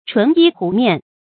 鹑衣鹄面 chún yī hú miàn
鹑衣鹄面发音